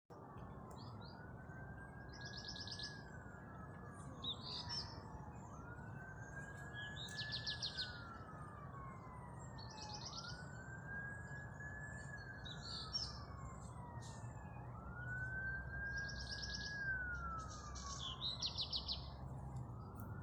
Birds -> Thrushes ->
Black Redstart, Phoenicurus ochruros
StatusVoice, calls heard